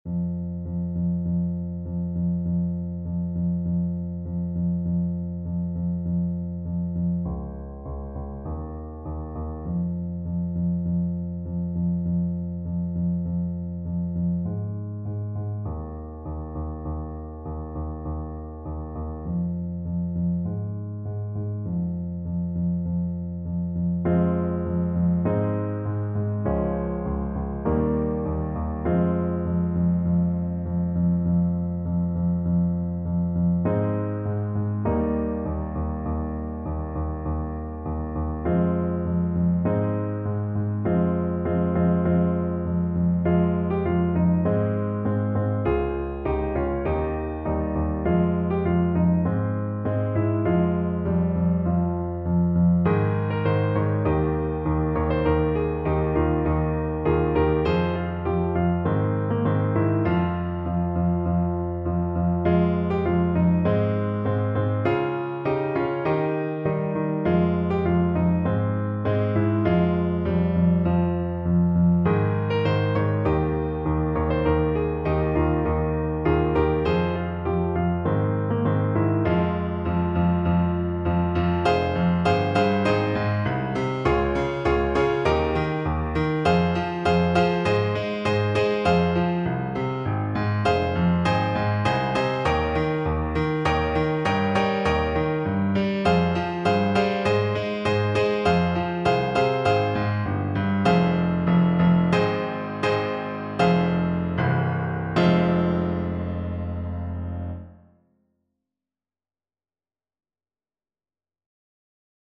Play (or use space bar on your keyboard) Pause Music Playalong - Piano Accompaniment Playalong Band Accompaniment not yet available transpose reset tempo print settings full screen
Alto Saxophone
F minor (Sounding Pitch) D minor (Alto Saxophone in Eb) (View more F minor Music for Saxophone )
Moderato =c.100
Classical (View more Classical Saxophone Music)